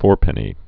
(fôrpĕnē, -pə-nē)